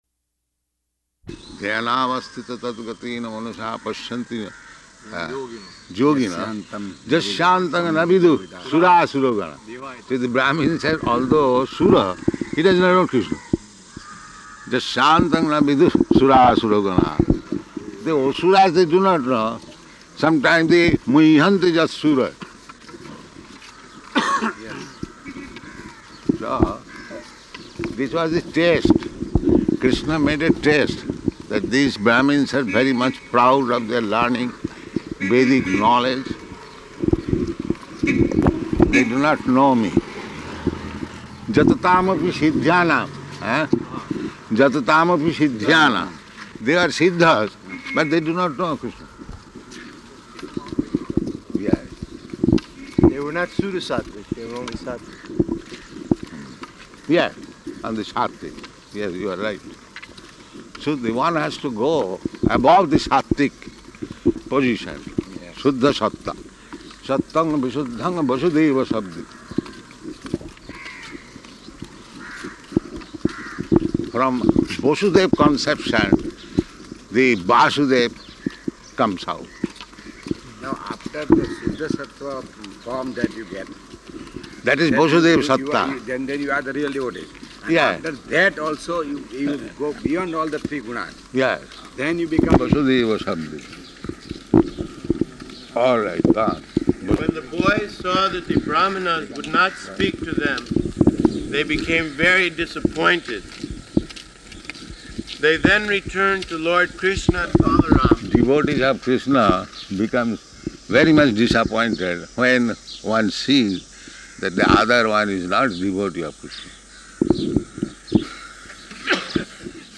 Type: Walk
Location: Bombay